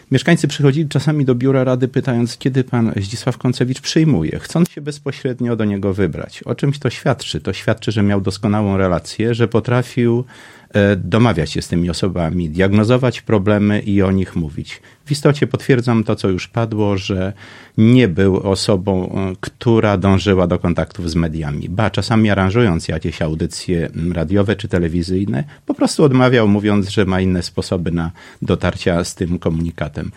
„Życzliwy, skromny, zaangażowany i skrupulatny”, tak Zdzisława Koncewicza, zmarłego suwalskiego samorządowca i związkowca, wspominali dziś w Radiu 5 Zdzisław Przełomiec, przewodniczący Rady Miejskiej w Suwałkach i Jacek Juszkiewicz, wiceprzewodniczący Rady oraz przewodniczący klubu Prawo i Sprawiedliwość.